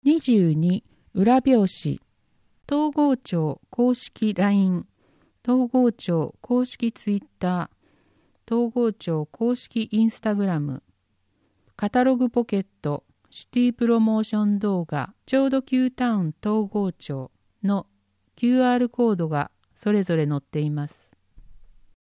広報とうごう音訳版（2020年1月号）